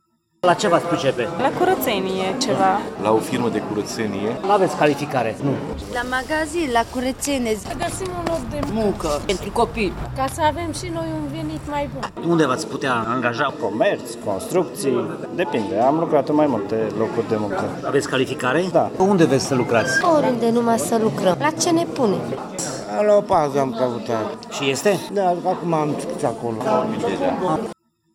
Evenimentul de azi, care este o premieră pentru Tg.Mureș, a fost organizat la Liceul Tehnologic Avram Iancu, fiind oferite locuri de muncă în general pentru persoane necalificate.